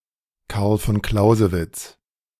Carl Philipp Gottlieb von Clausewitz[note 1] (/ˈklzəvɪts/ KLOW-zə-vits, German: [ˈkaʁl fɔn ˈklaʊzəvɪts]